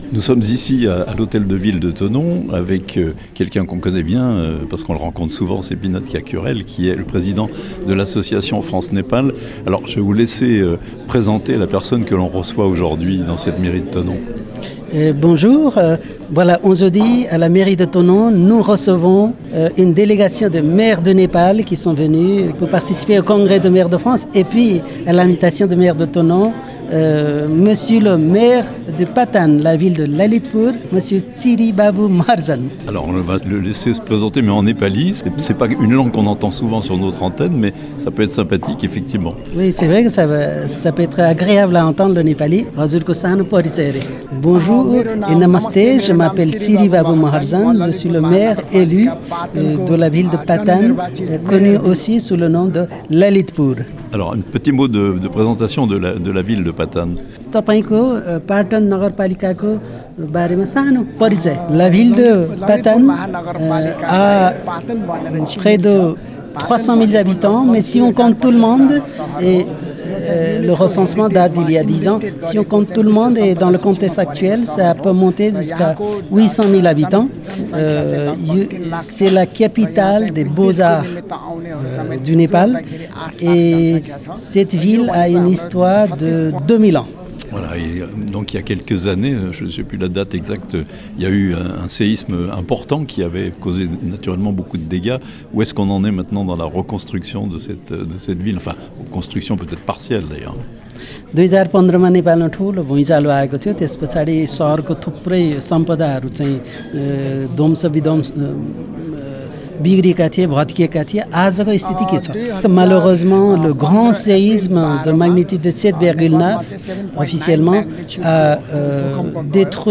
Visite thononaise pour le Maire de la ville de Patan (Népal)-interview